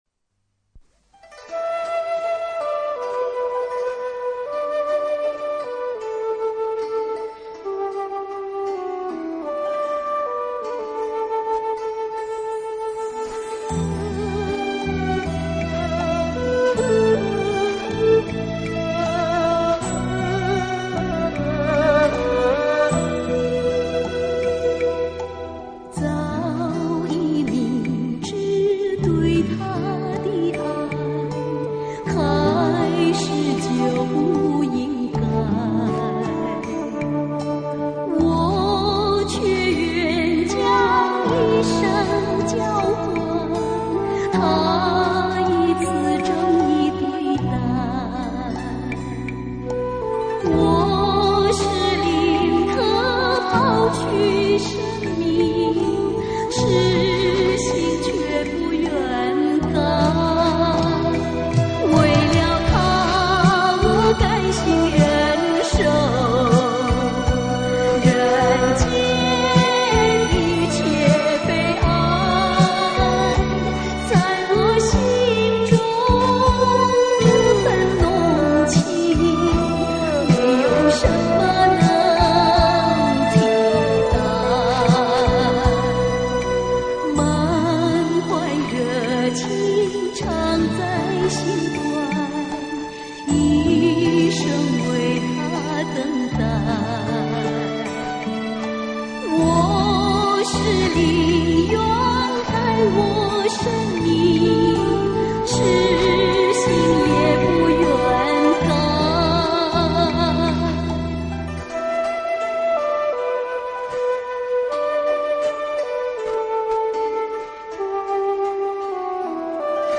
音质不大好，看到有歌迷找此歌，发上试听。